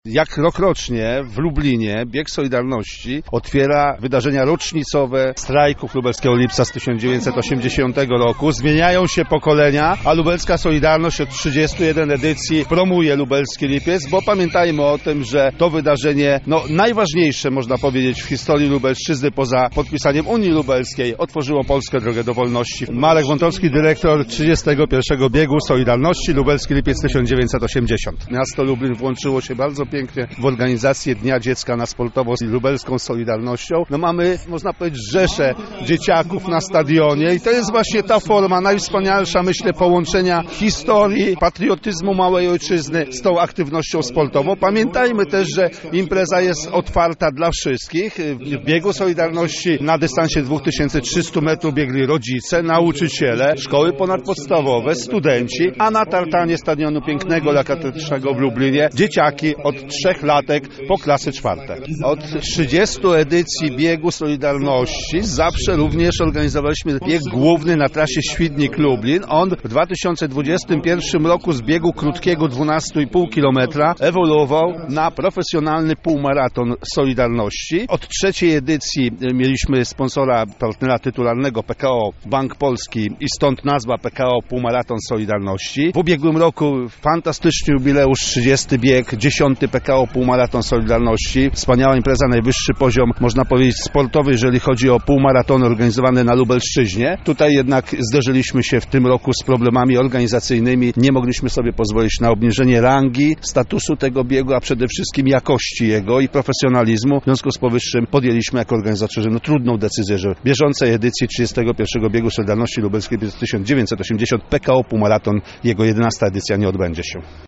O tym jak ważne jest to wydarzenie na sportowej mapie Lublina, mówią organizatorzy.